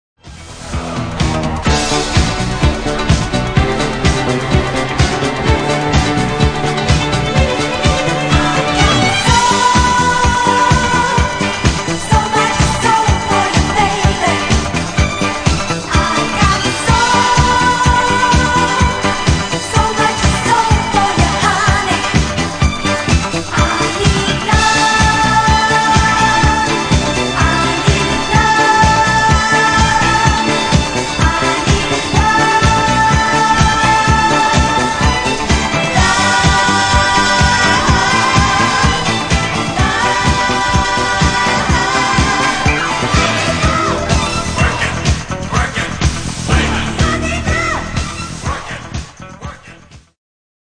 Genere:   Disco Funky